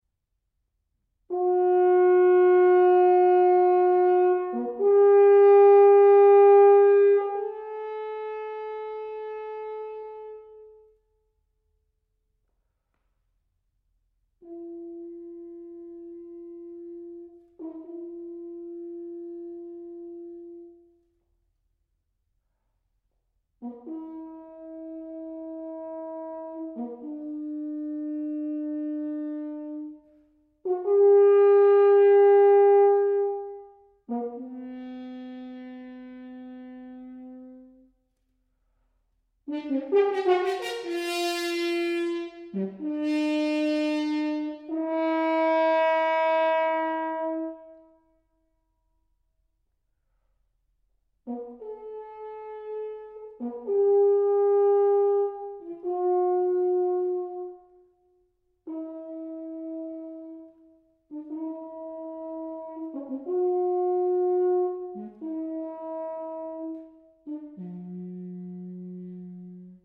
UK based natural hornist